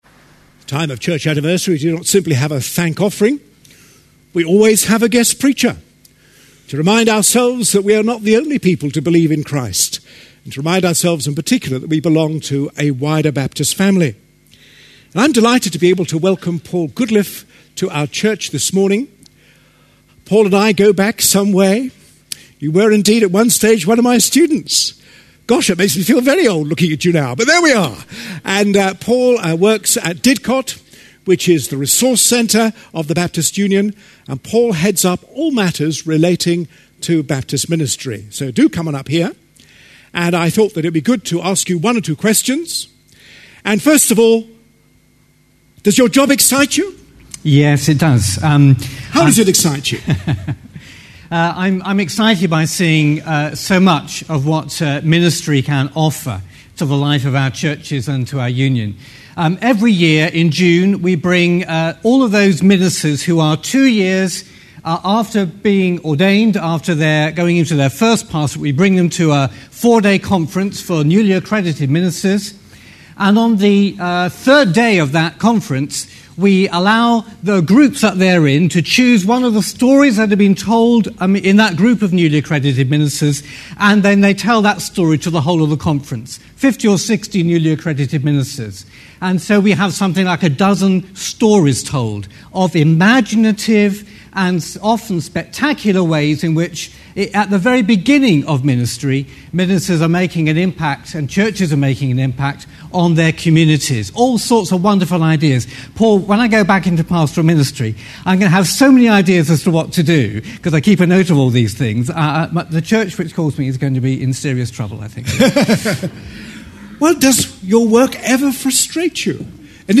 A sermon preached on 8th May, 2011.